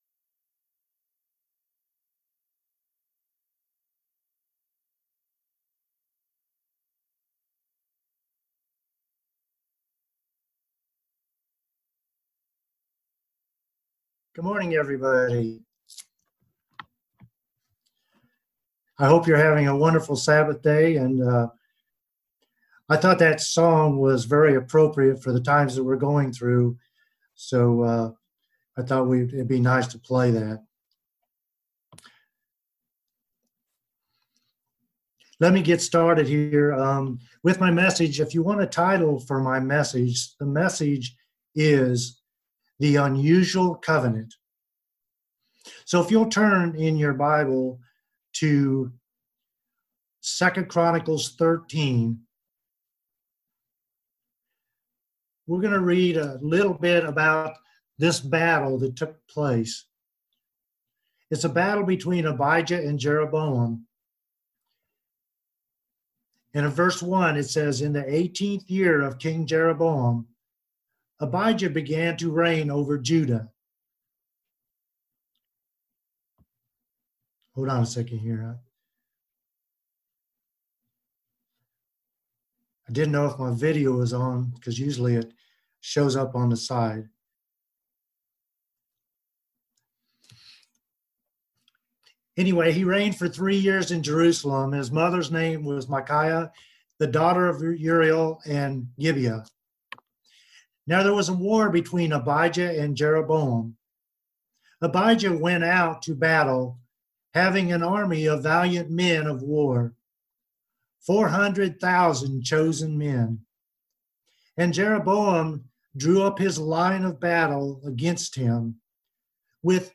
Sermons
Given in Laurel, MS New Orleans, LA